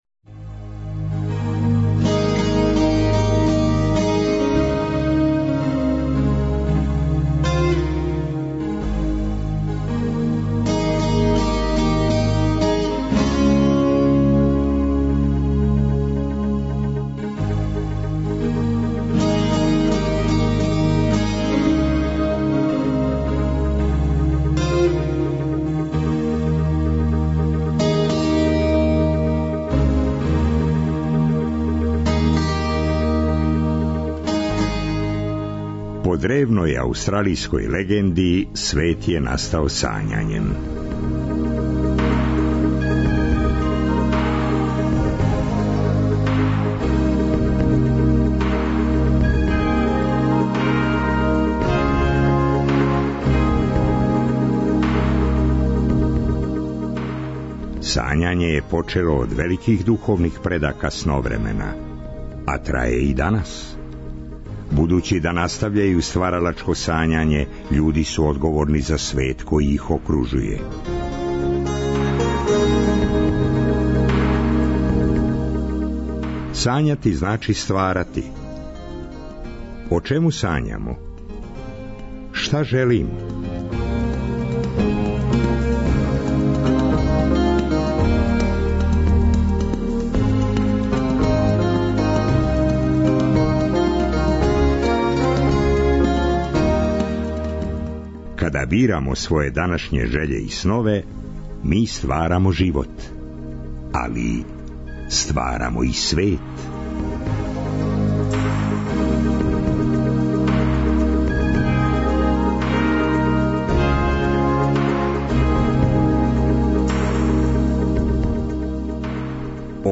О томе и још много чему испричали су нам наши спортисти, учесници Олимпијаде 2012. Њихове приче, емитоване у оквиру програма за младе, сачуване су у богатој архиви Радио Београда 1.